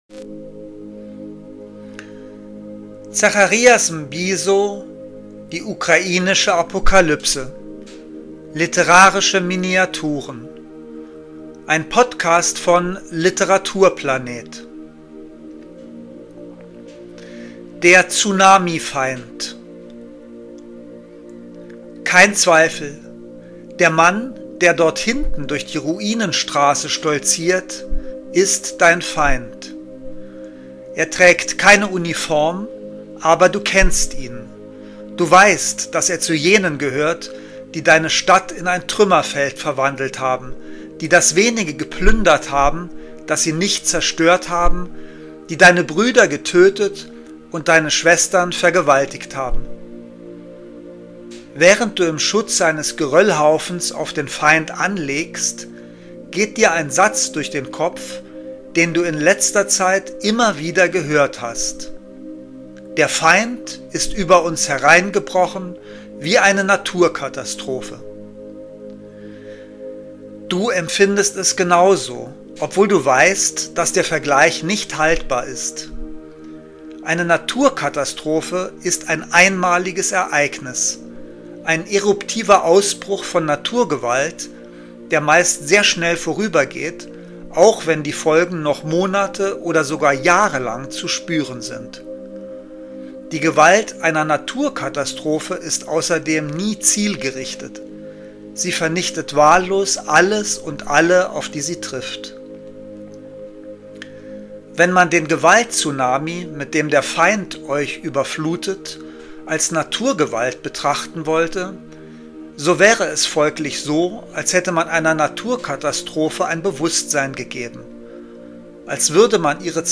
Lesung.